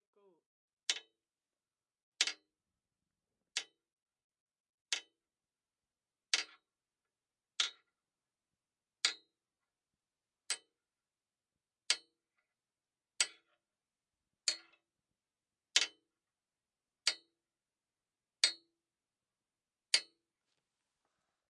毁灭，木头，A
标签： 命中 命中 崩溃 崩溃 摧毁 捕捉 破坏 破坏 毁灭 木材 破坏 木材 弹响 破坏 围栏 护栏 冲击 冲击
声道立体声